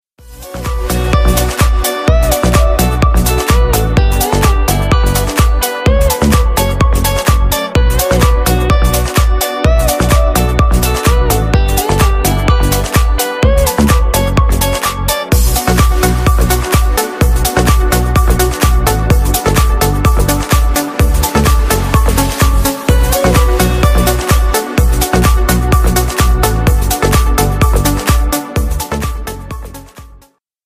Клубные Рингтоны » # Рингтоны Без Слов
Рингтоны Ремиксы » # Танцевальные Рингтоны